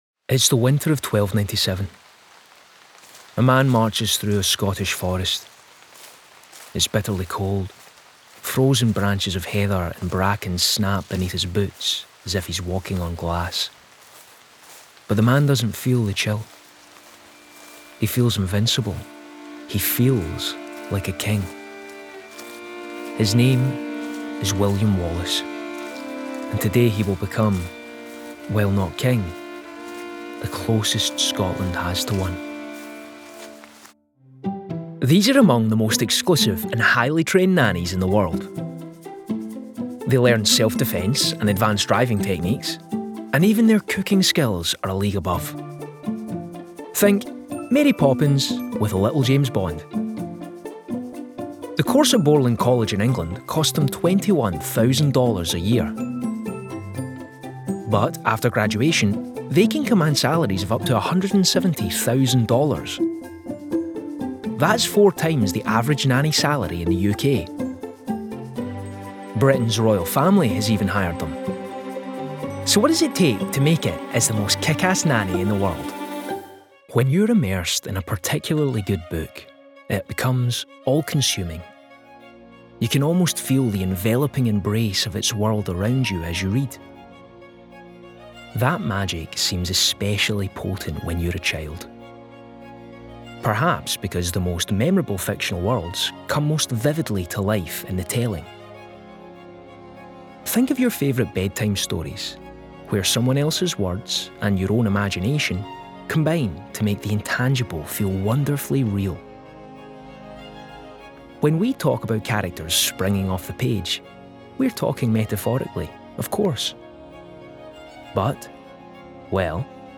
Narration Showreel
Male
Scottish
Glasgow
Friendly
Warm
Confident
Down To Earth